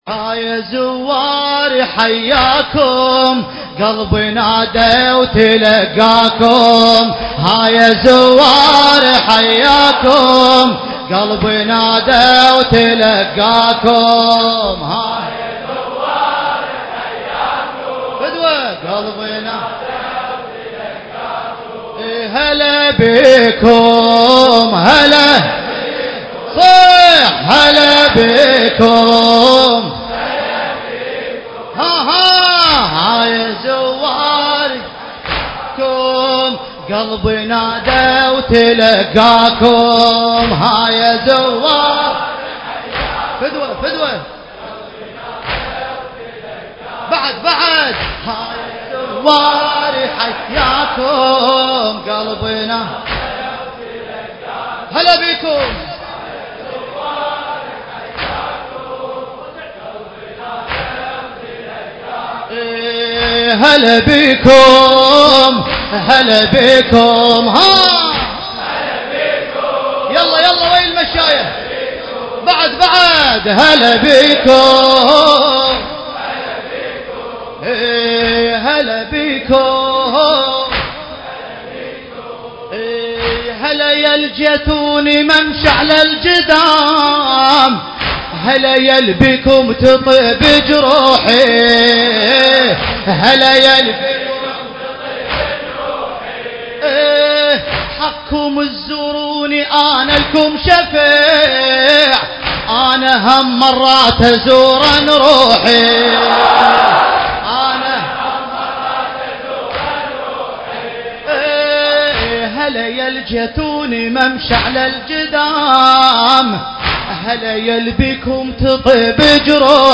المراثي
الحجم 3.28 MB المكان: حسينية داود العاشور- البصرة التاريخ: 2016